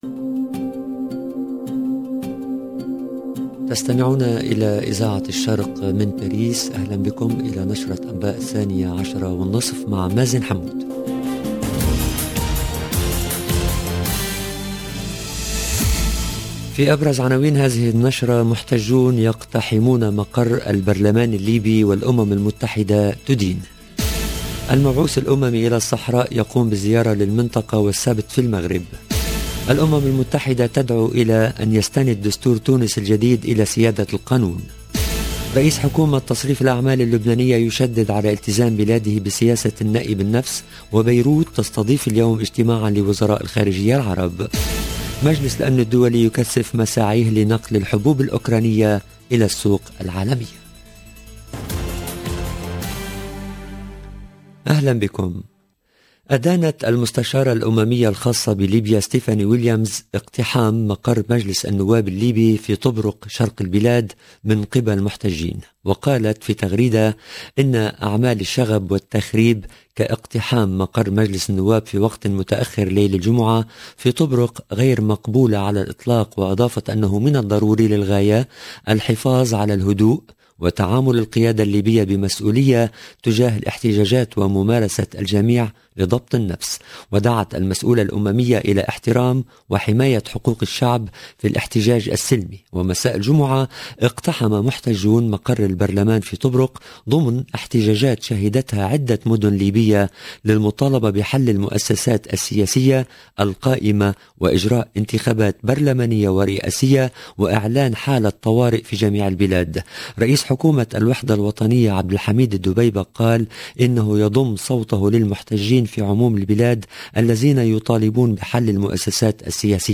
LE JOURNAL DE MIDI 30 EN LANGUE ARABE DU 2/07/22